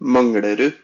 pronunciation) is a borough in the Østensjø district of Oslo, Norway.
No-Manglerud.ogg.mp3